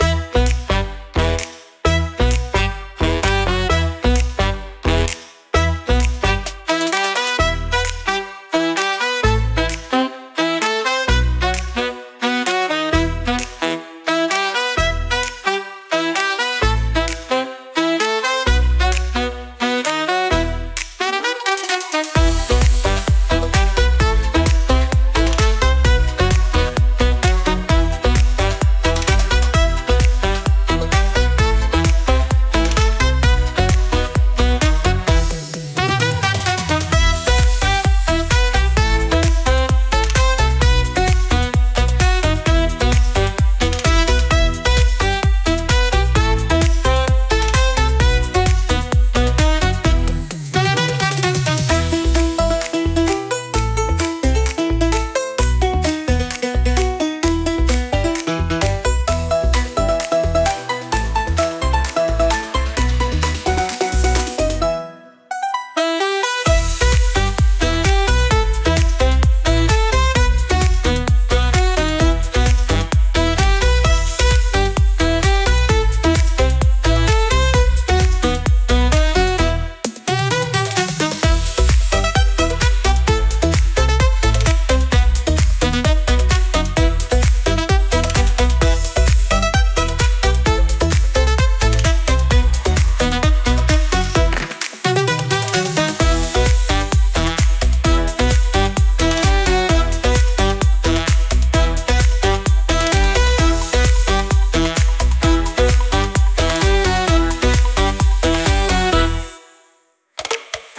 An upbeat and cheerful track designed for junior gymnasts.
ジュニア選手が一番踊りやすい絶妙なテンポ感で作られており、無理なくリズムに乗って「ノリノリ」で踊ることができます。
観客も思わず手拍子したくなるような、チャーミングなエネルギーにあふれています。